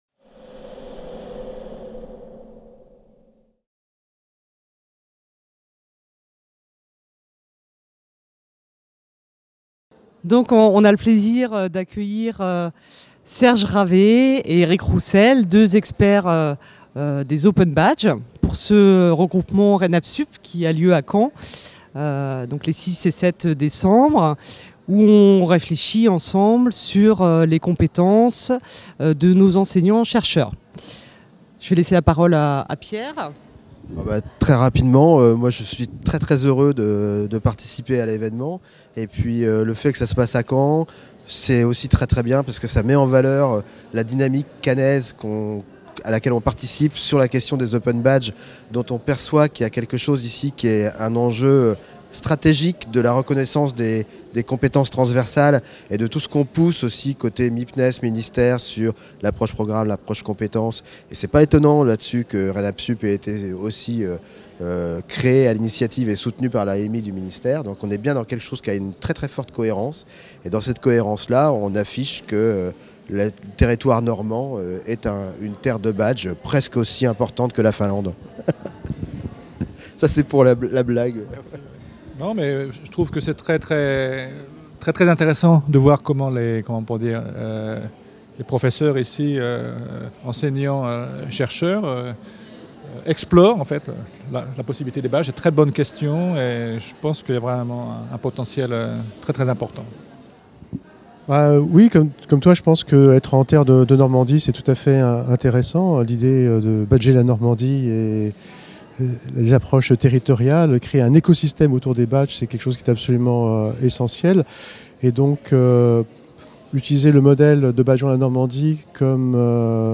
RéNAPS'up 2017 - 08 interview journée atelier Open Badges | Canal U